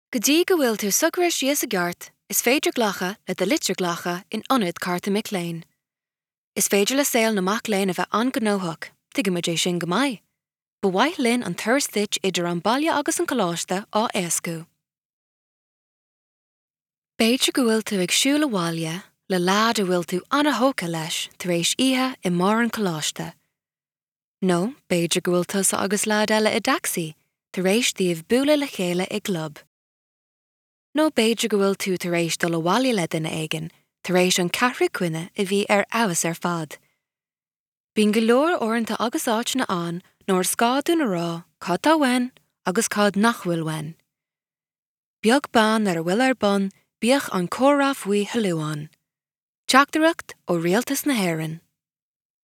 Voice samples
Irish Reel